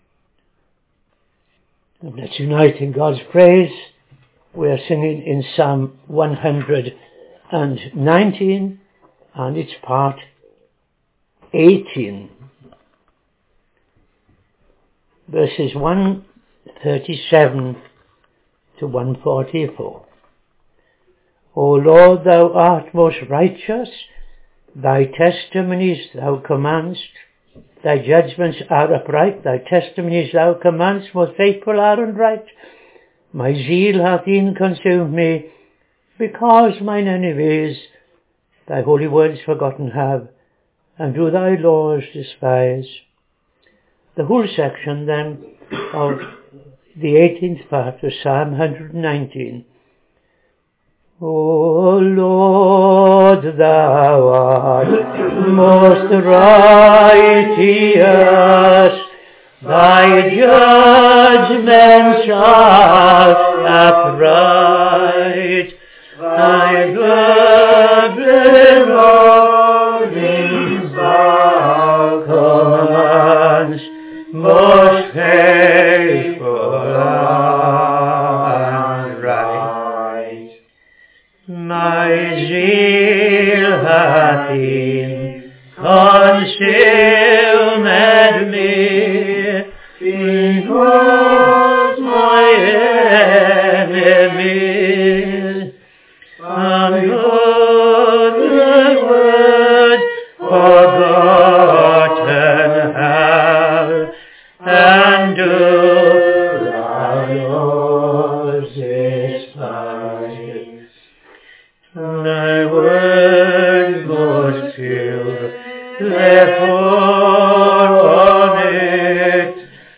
Evening Service - TFCChurch
Psalm 9:1-9 ‘LORD, thee I’ll praise with all my heart’ Tune Stroudwater